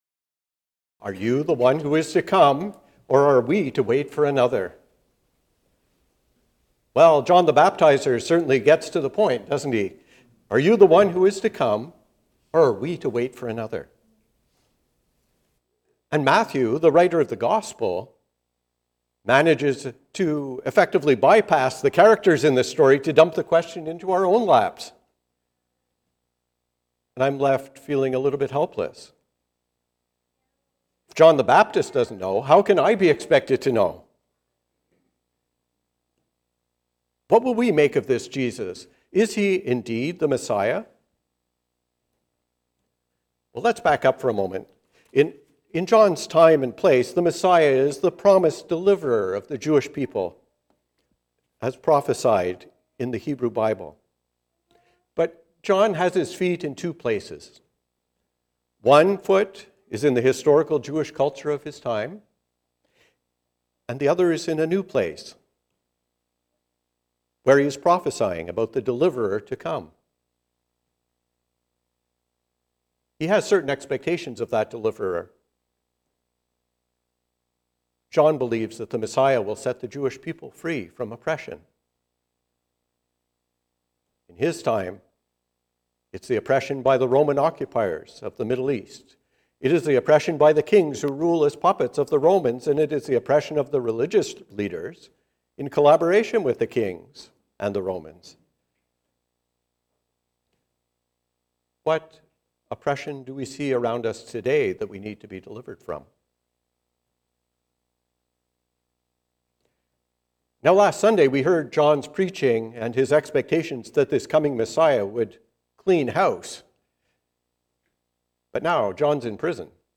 Who are we expecting? A sermon on Matthew 11:2-11